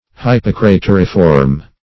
Search Result for " hypocrateriform" : The Collaborative International Dictionary of English v.0.48: Hypocrateriform \Hyp`o*cra*ter"i*form\, a. [Pref. hypo- + krath`r cup + -form.]